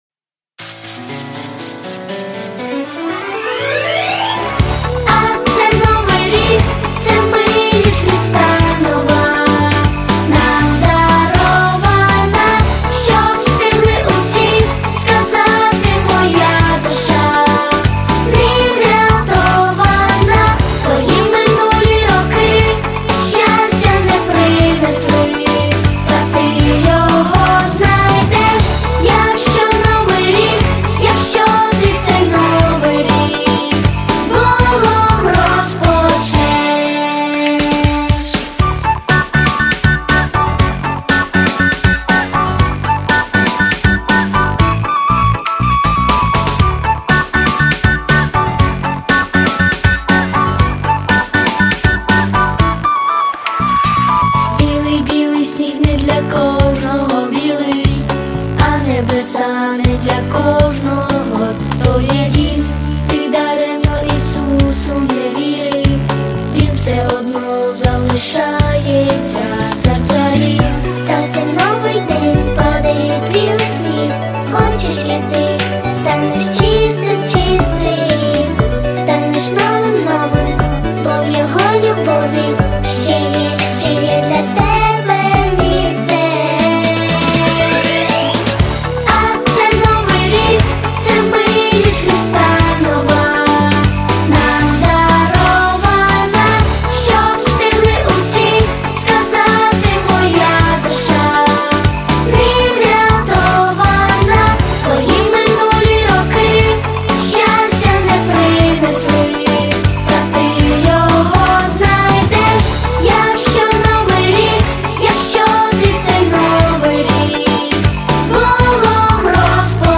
• Качество: Хорошее
• Жанр: Детские песни
христианские песни